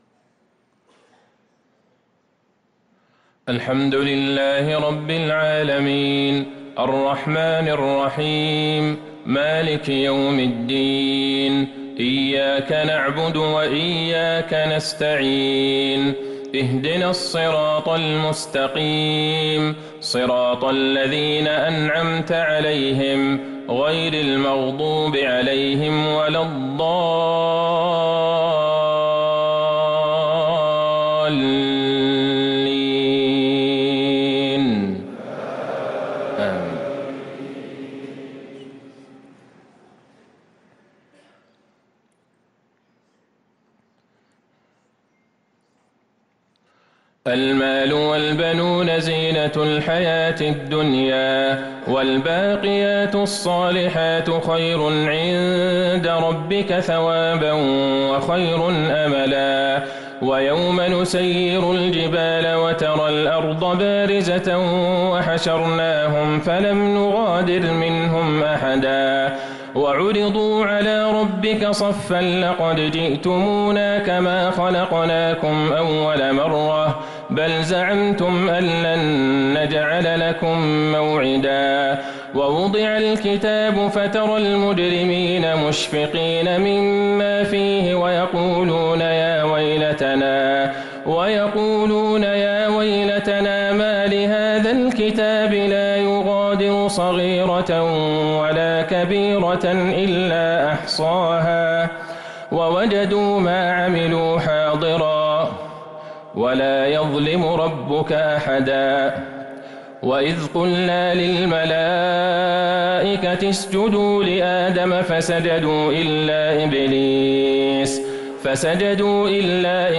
صلاة العشاء للقارئ عبدالله البعيجان 3 جمادي الأول 1445 هـ
تِلَاوَات الْحَرَمَيْن .